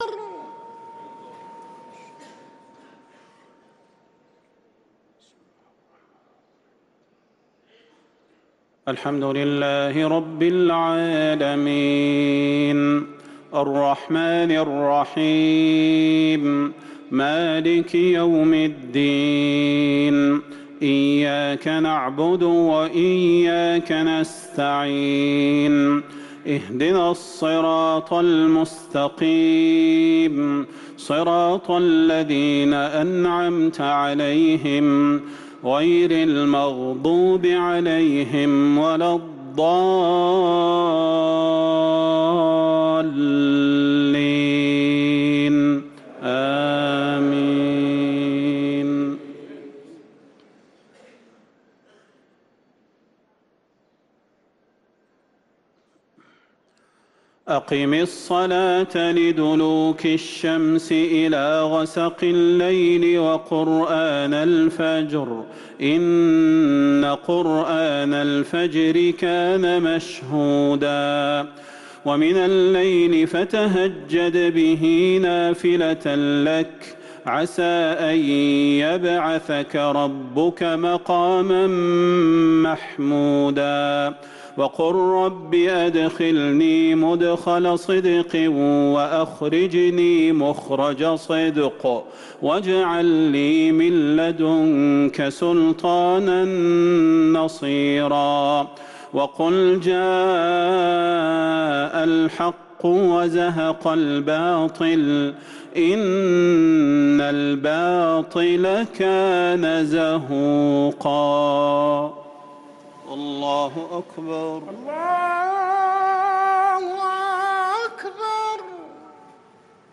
صلاة المغرب للقارئ صلاح البدير 21 ربيع الآخر 1445 هـ